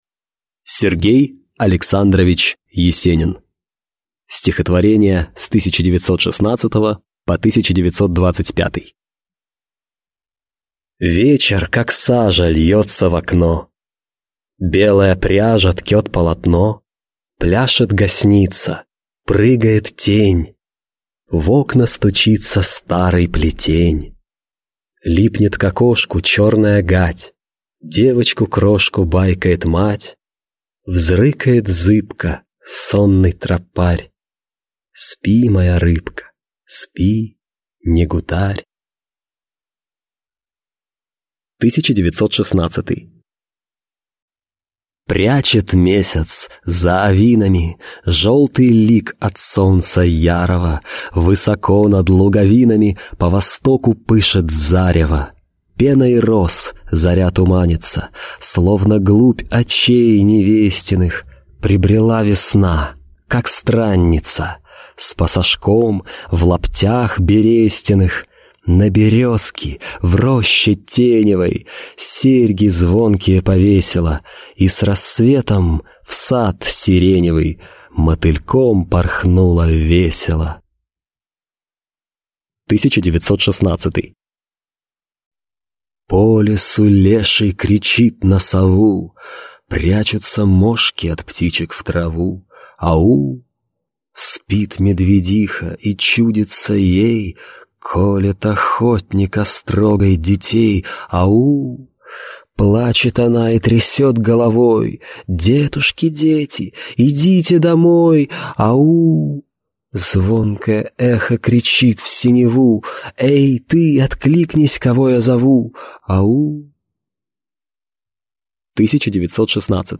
Аудиокнига Стихотворения 1916 – 1925 | Библиотека аудиокниг